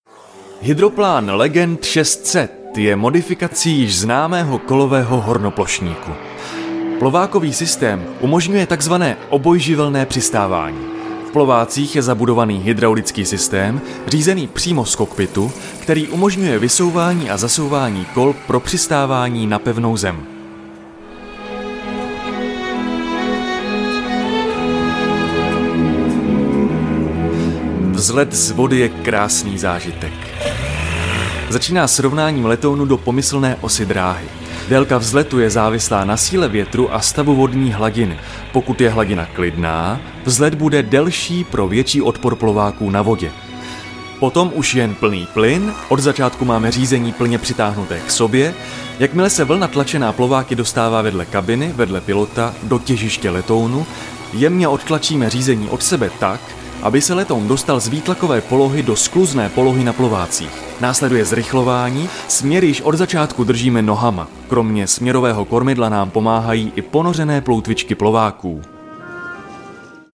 VOICE OVER / KOMENTÁŘ / DABING / ZPĚV
1. KOMENTÁŘ-Aeropilot.mp3